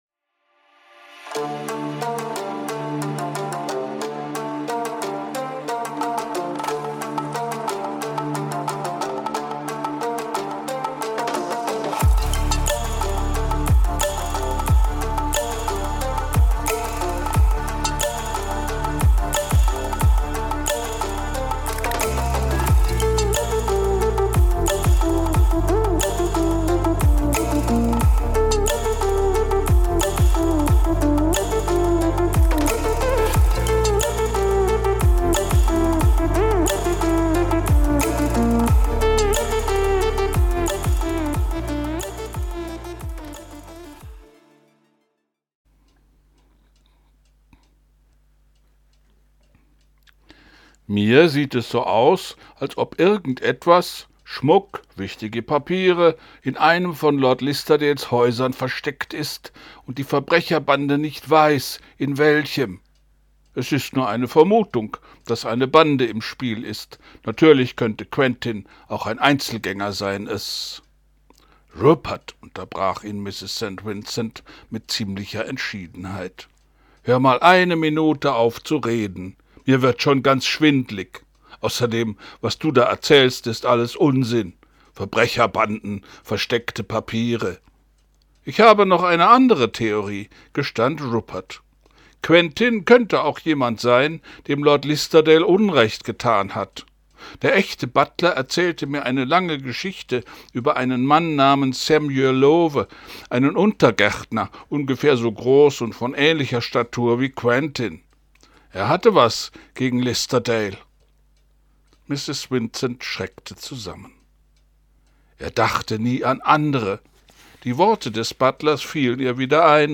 ich lese vor christie etwas...